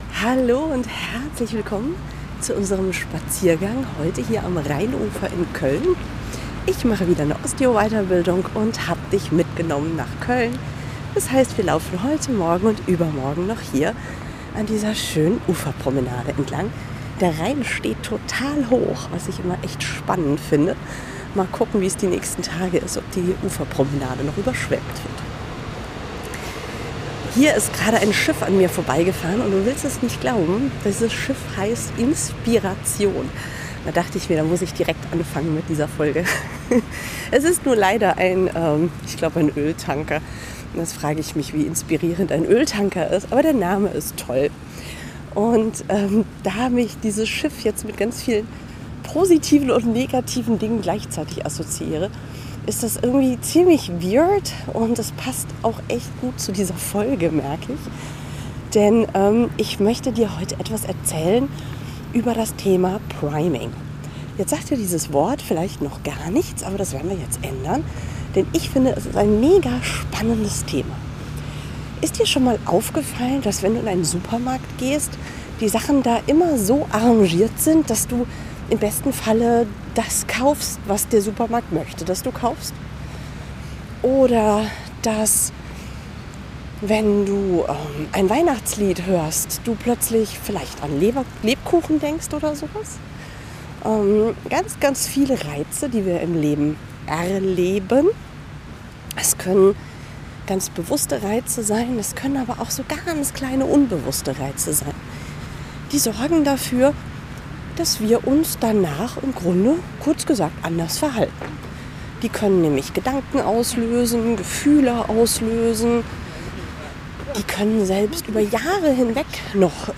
Heute begleitest du mich am Rheinufer und wir unterhalten uns über